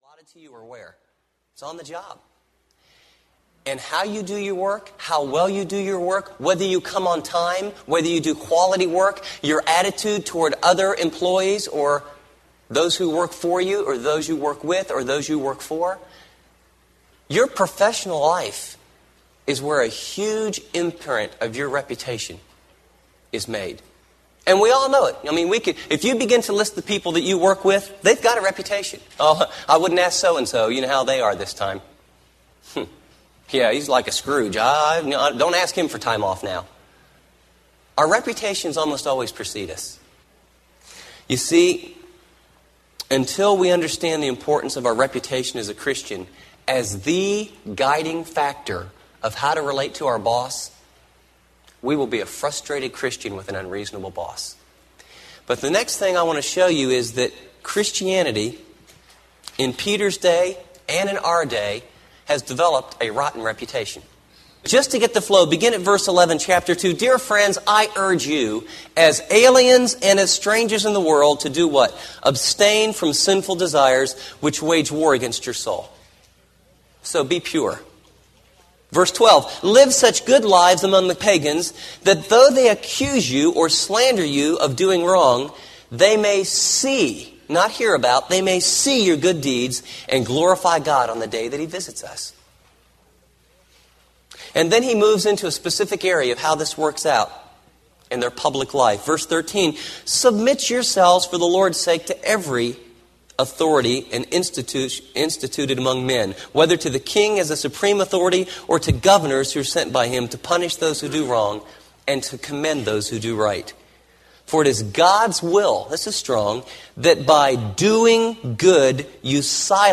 When Life’s Not Fair Teaching Series Audiobook
Narrator